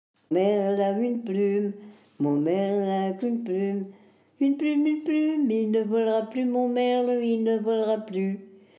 Origine : Normandie (Eure) Année de l'arrangement : 2020
Collecte à La verte Colline (EHPAD, Eure )